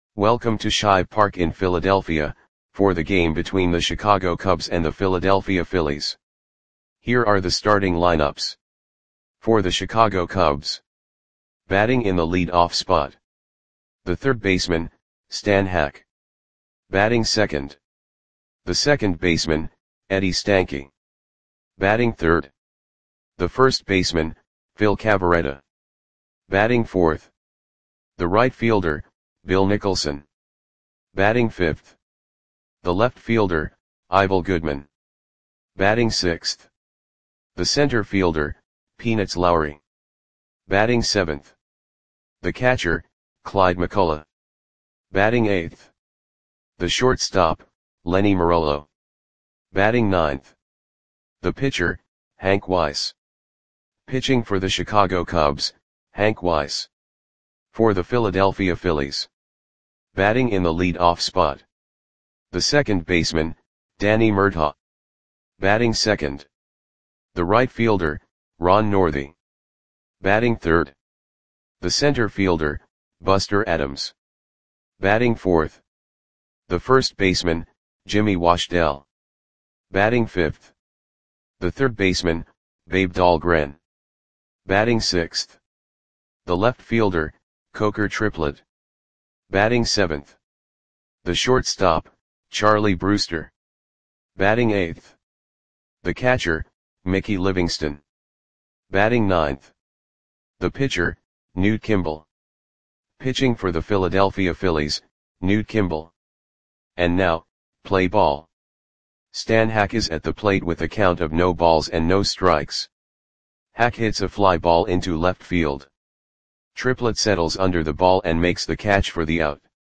Audio Play-by-Play for Philadelphia Phillies on July 3, 1943
Click the button below to listen to the audio play-by-play.